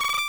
UI SFX defines confirm, accept, forward, back, and bonk sounds
ui_accept_v2.wav